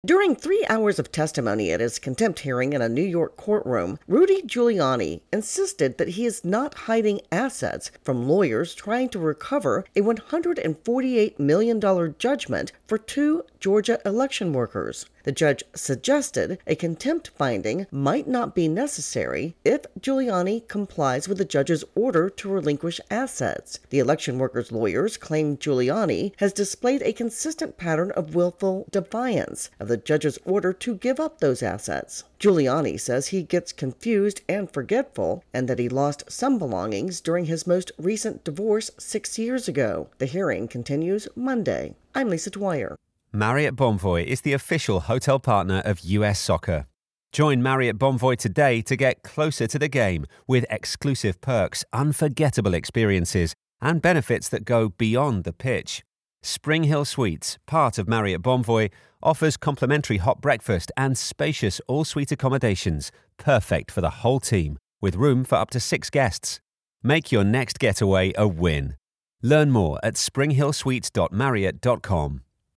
reports on the latest in the contempt hearings for Rudy Giuliani .